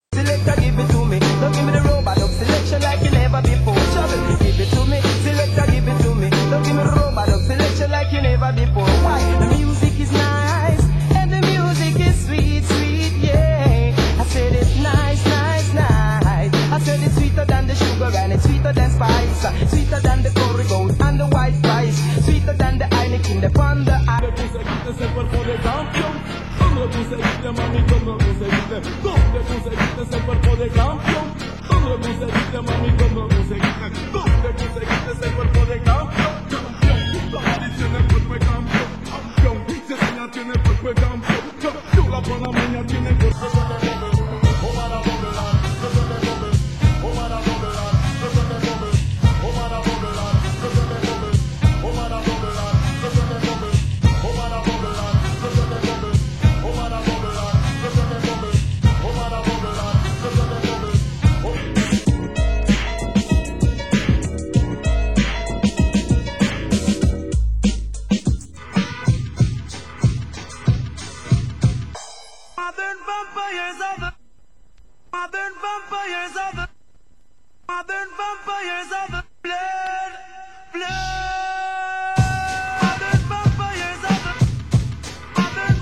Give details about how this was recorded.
Format: Vinyl 12 Inch